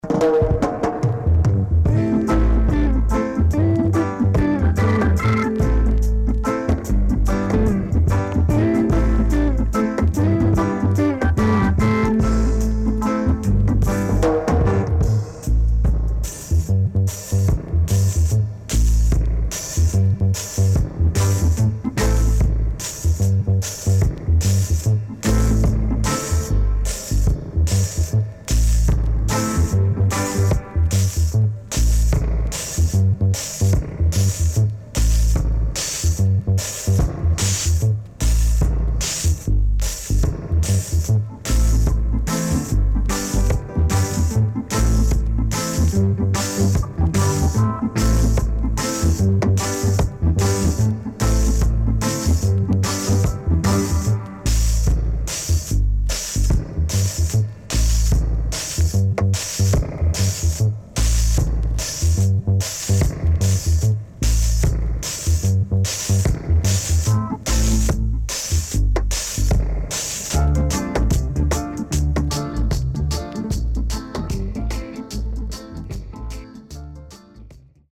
SIDE A:所々チリノイズがあり、少しプチノイズ入ります。